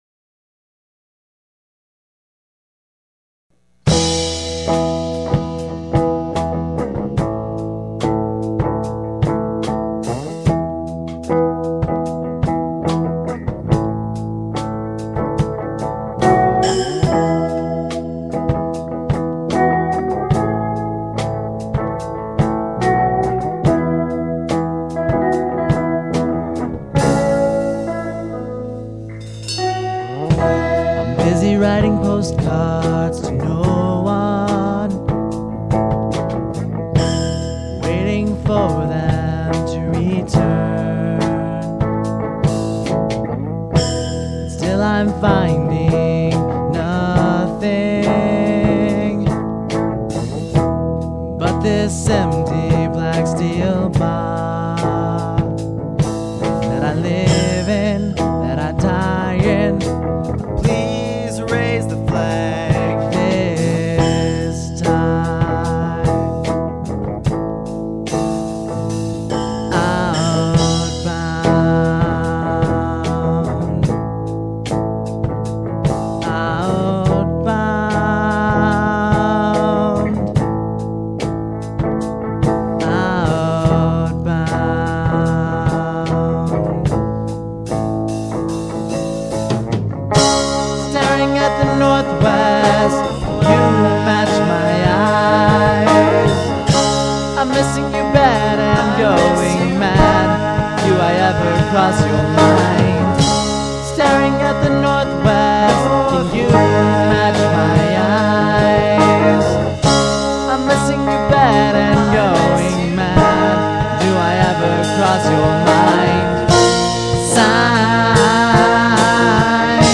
SORRY FOR POOR MP3S, OUR EP DROPS JANUARY 2004